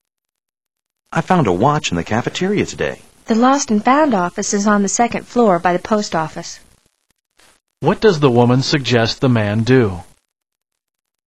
What does the woman suggest the man do?